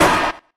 mech_hurt_1.ogg